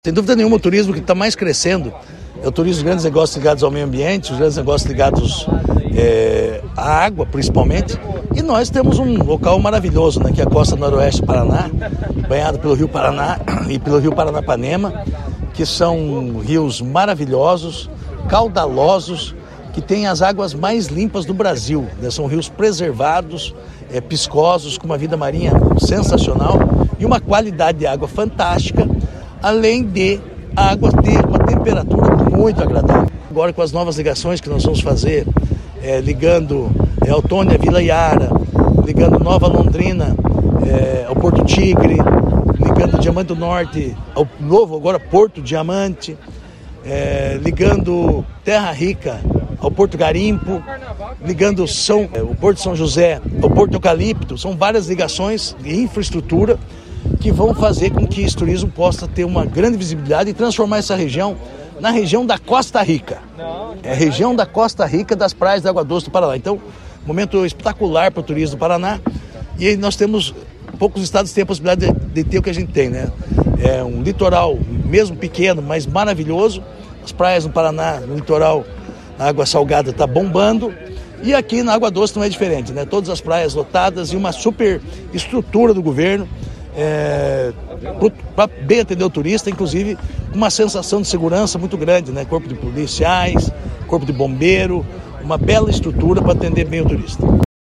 Sonora do secretário do Turismo, Marcio Nunes, sobre a instalação da estrutura de lazer e segurança em balneários do Noroeste